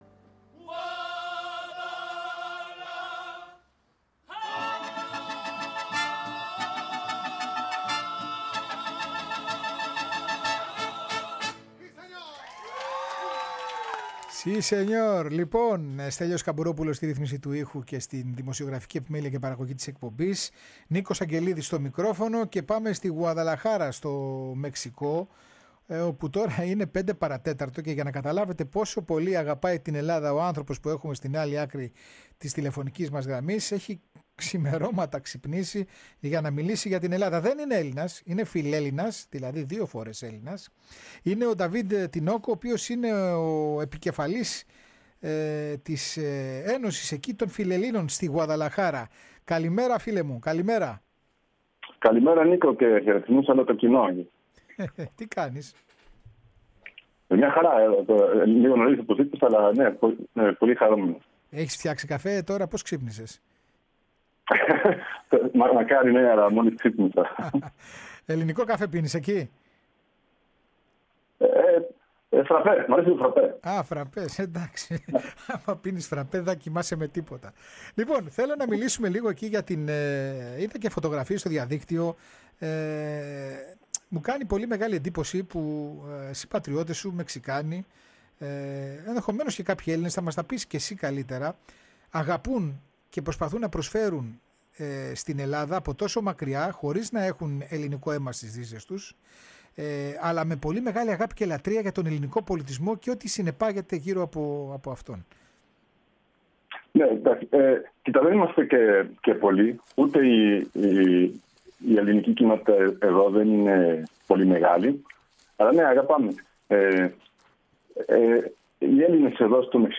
Ζωντανή σύνδεση της εκπομπής Ώρα Ελλάδας